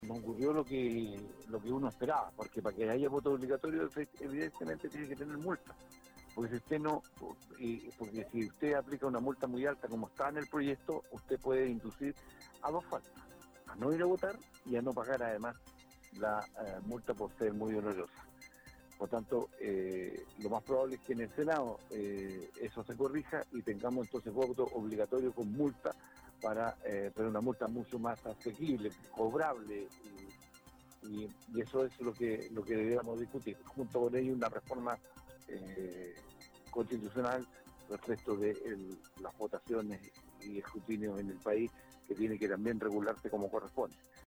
En entrevista con Radio UdeC, Saavedra advirtió que, sin sanciones claras, la obligatoriedad del sufragio queda en entredicho.